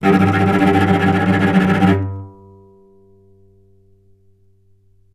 vc_trm-G2-mf.aif